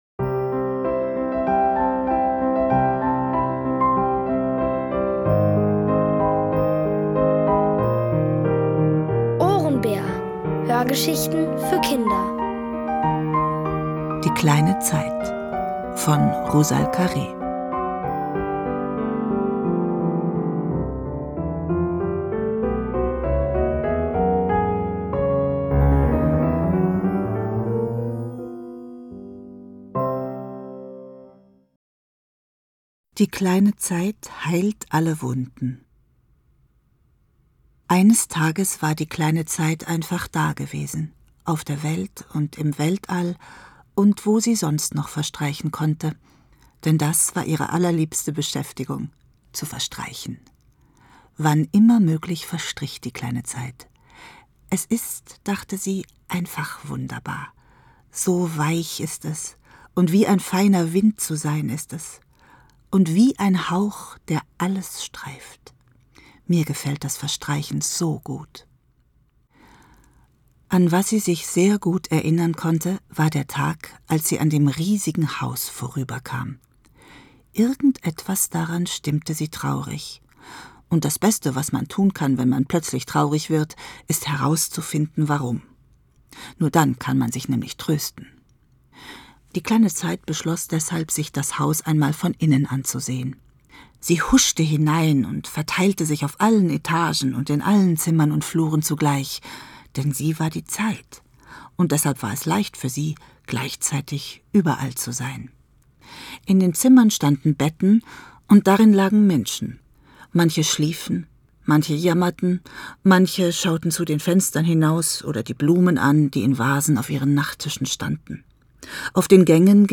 Es liest: Martina Gedeck.